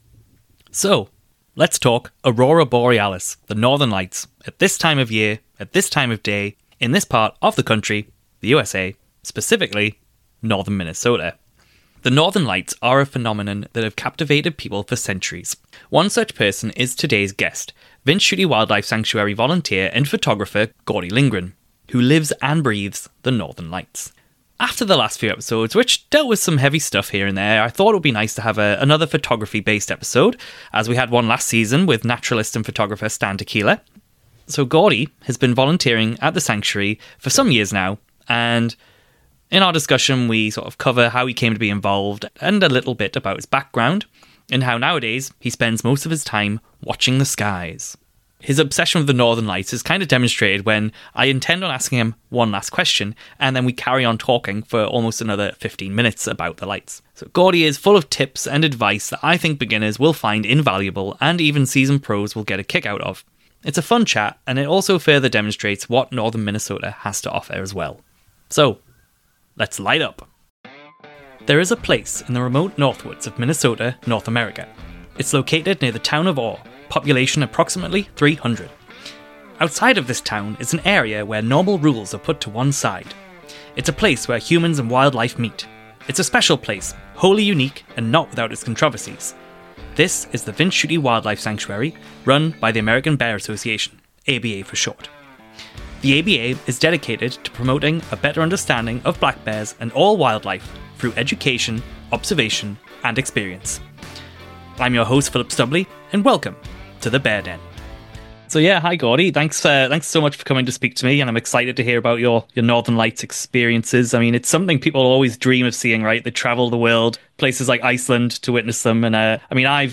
In this conversation, he shares his experiences volunteering with black bears, his passion for photographing wildlife, and his expert insights into shooting the Northern Lights.